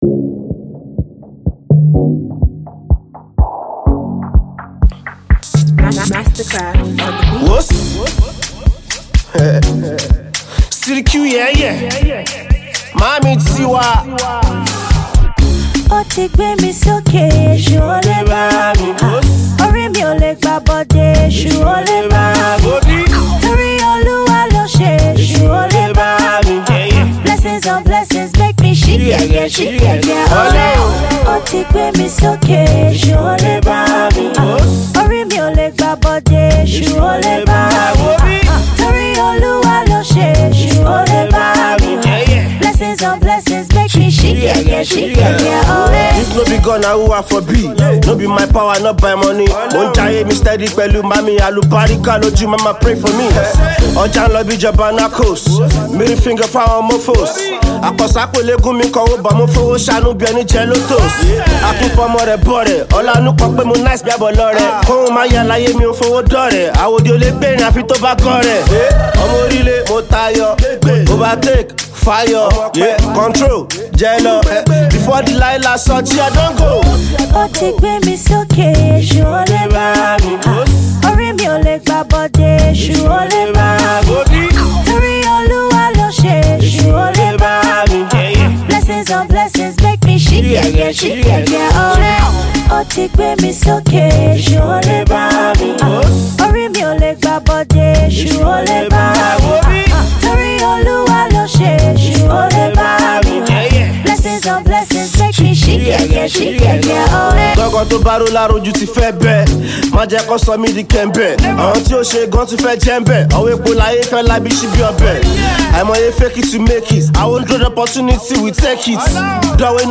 dope gratitude track